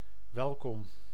Ääntäminen
Synonyymit fidēlis Ääntäminen Classical: IPA: /aˈmiː.kus/ Haettu sana löytyi näillä lähdekielillä: latina Käännös Ääninäyte 1. welkom {n} 2. welkome 3. graag gezien 4. vriend {m} 5. lief {n} Suku: m .